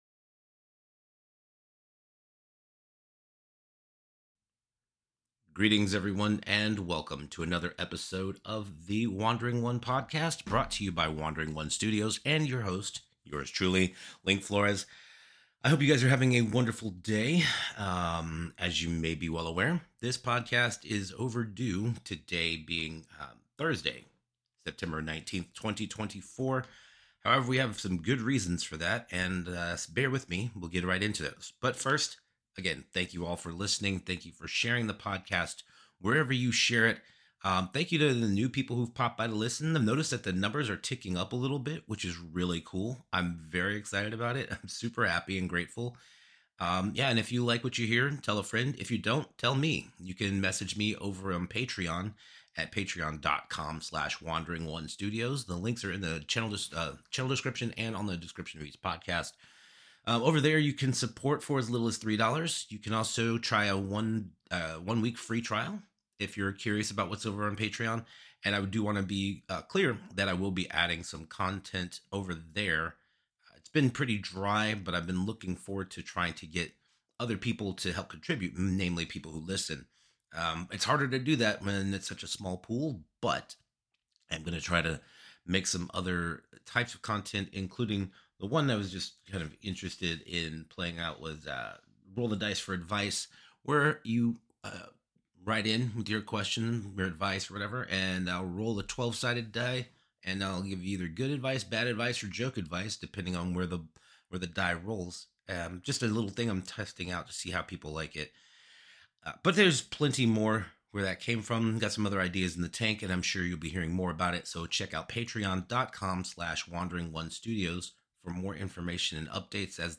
<<< WARNING: May contain adult language and thematic content. Listener discretion is advised. >>>